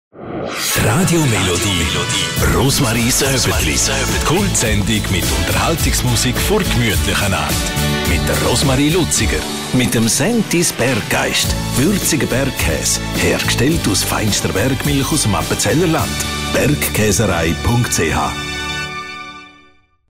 Mit volkstümlichem Schlager, stimmungsvoller Unterhaltungsmusik und spannenden Mitmachaktionen erreichen Sie eine treue und vielseitige Hörerschaft.